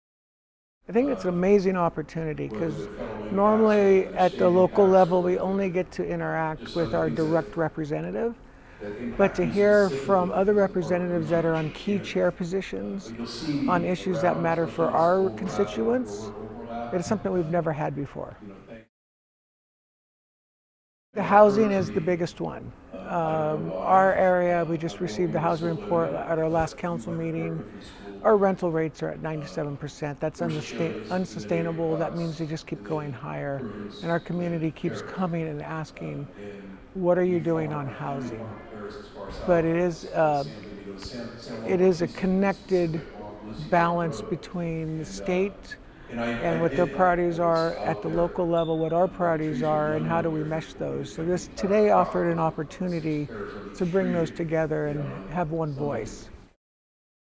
For radio news outlets who would like to air this story, the following links are soundbites of the State Legislative Issues Day in English and Spanish
Mike LeBarre, Mayor of King City (two cuts) :57